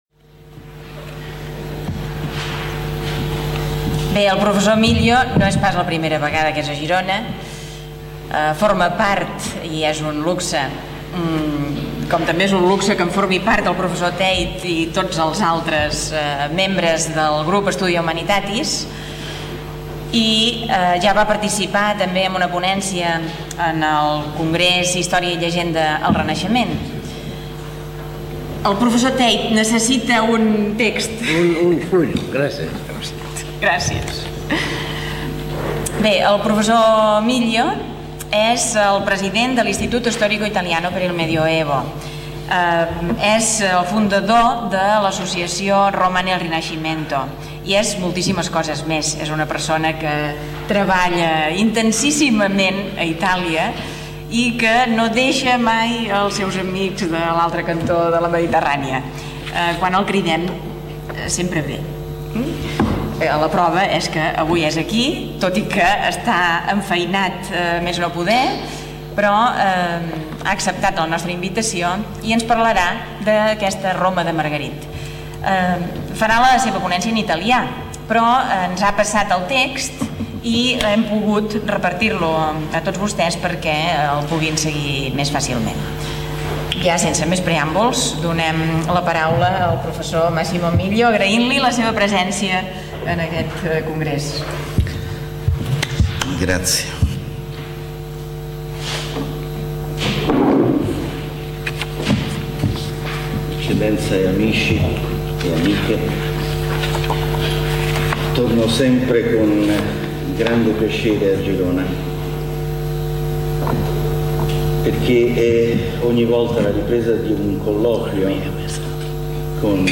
Simposi dedicat al personatge de Joan Margarit